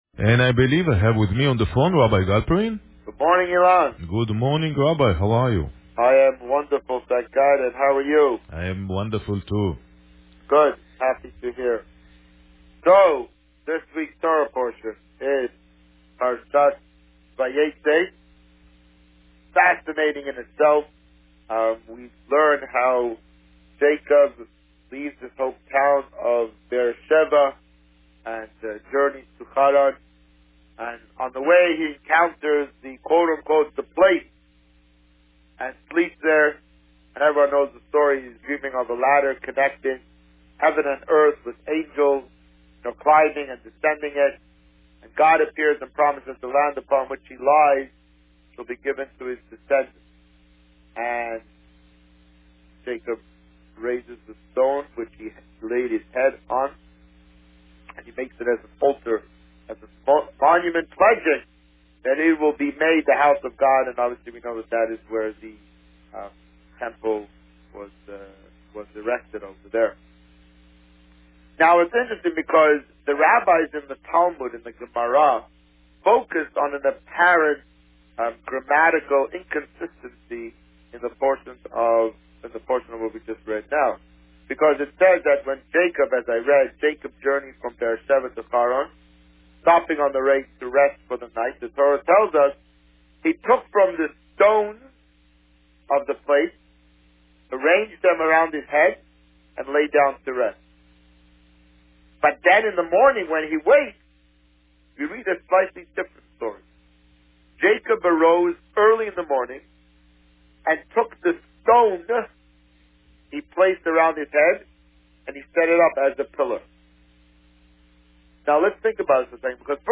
This week, the Rabbi spoke about Parsha Vayeitzei and about the upcoming Chanukah project of collecting donations for the Windsor Fire Department's charity and lighting a menorah in their honour at Devonshire Mall. Listen to the interview here.